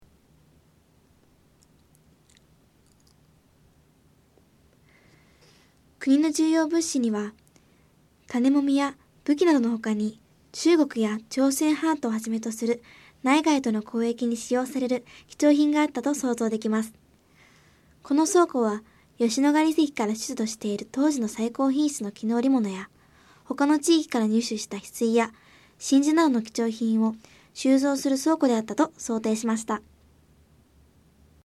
この倉庫は吉野ヶ里遺跡から出土している当時の最高品質の絹織物や、他の地域から入手した翡翠（ひすい）や真珠などの貴重品を収蔵する倉庫であったと想定しました。 音声ガイド 前のページ 次のページ ケータイガイドトップへ (C)YOSHINOGARI HISTORICAL PARK